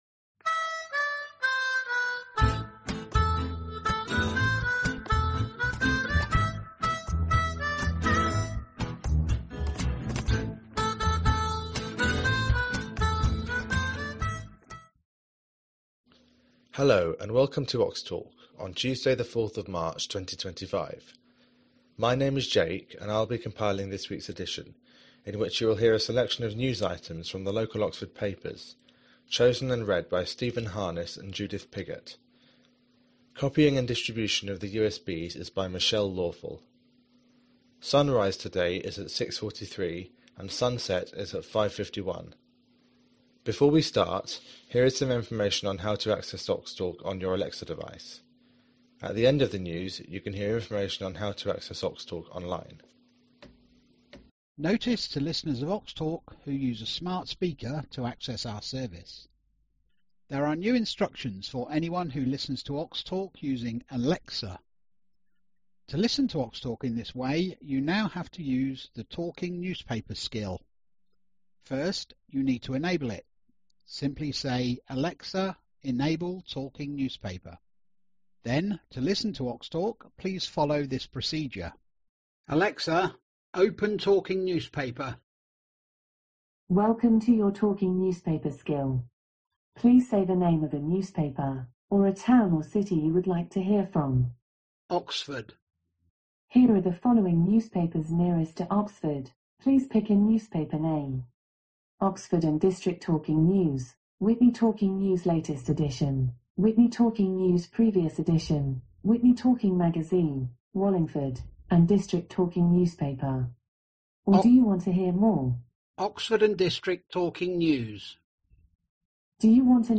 4th March 2025 edition - Oxtalk - Talking newspapers for blind and visually impaired people in Oxford & district